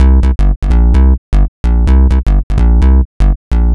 Jackin Saw C 128.wav